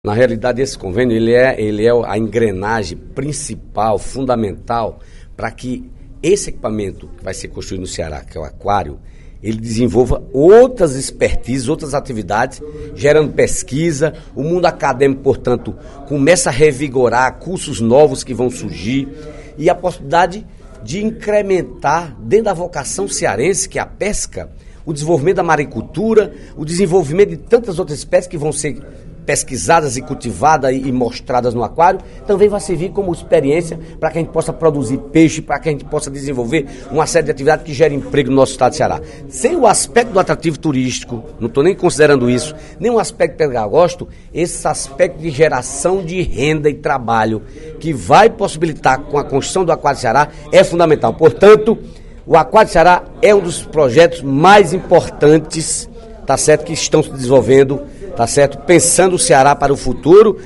O deputado Dedé Teixeira (PT) informou, na sessão plenária desta sexta-feira (23/03) da Assembleia Legislativa, que a Secretaria Estadual do Turismo (Setur) firmou, ontem, termo de cooperação técnica com o Instituto de Ciências do Mar (Labomar), da Universidade Federal do Ceará (UFC).